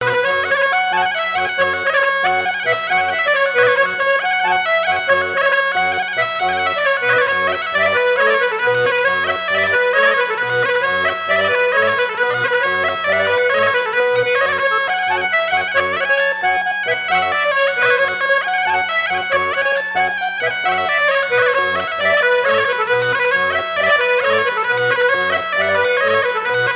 Couplets à danser
branle : courante, maraîchine
Pièce musicale éditée